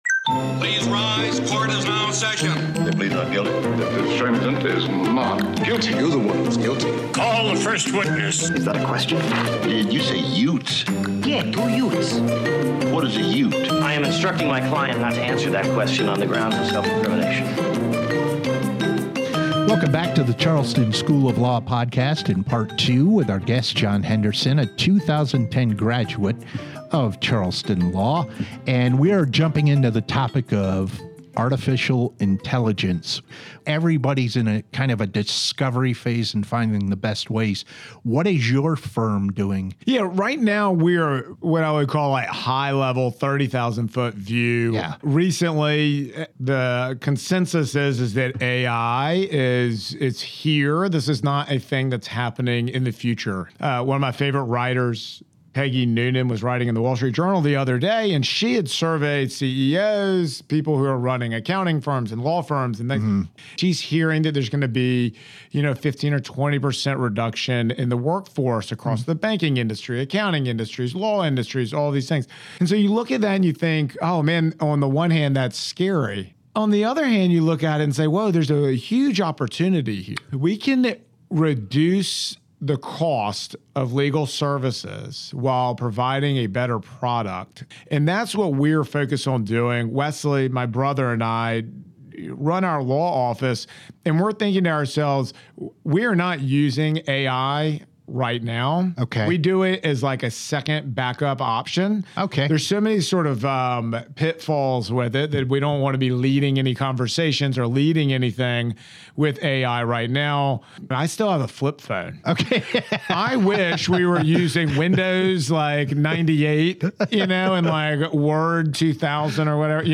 The Charleston School of Law podcast is a thought-provoking conversation about today's legal profession. Listen to experienced attorney's share their thoughts and insights.